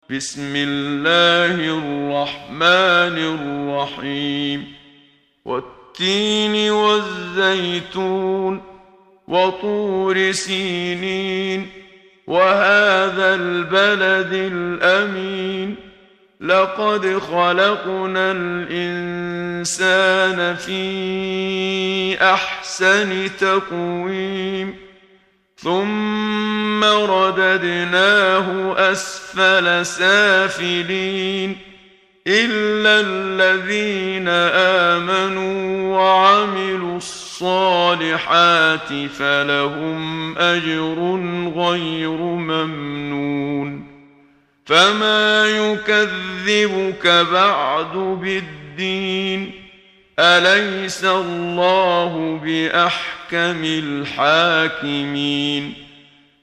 محمد صديق المنشاوي – ترتيل – الصفحة 8 – دعاة خير